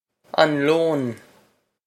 an lón on lone
on lone
This is an approximate phonetic pronunciation of the phrase.